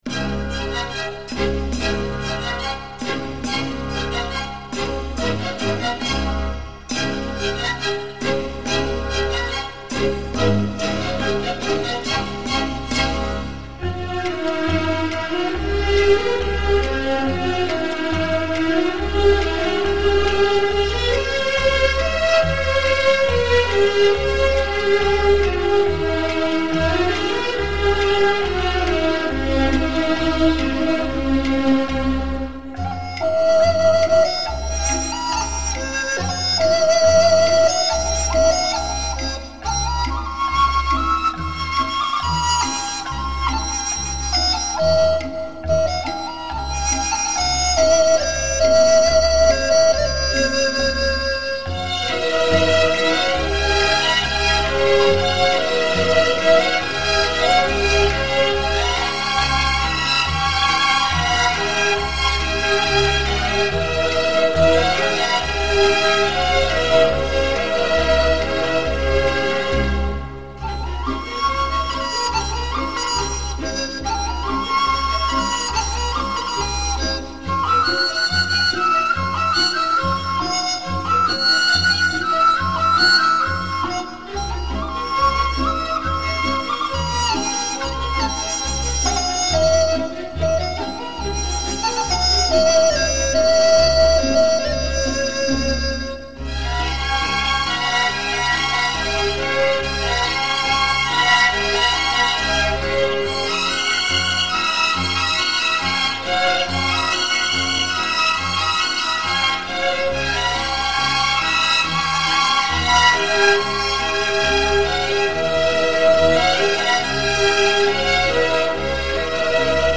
国乐/民族